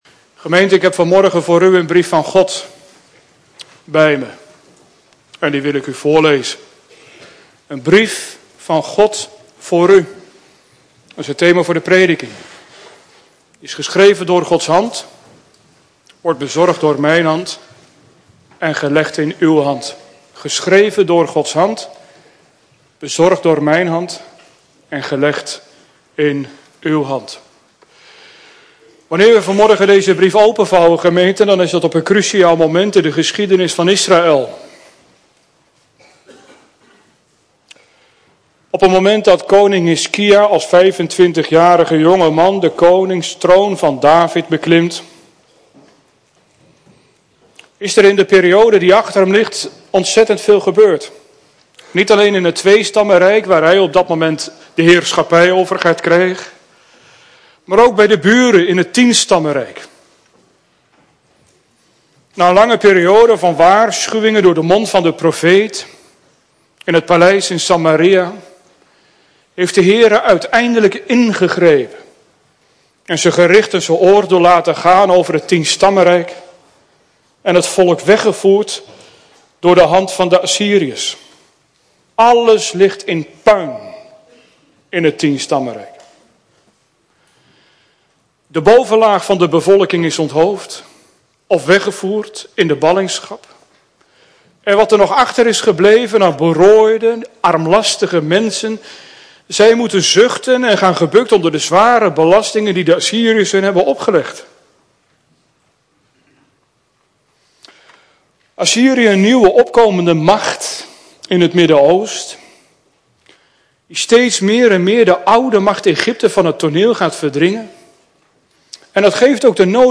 Prekenarchief | CGK Beth-El Sliedrecht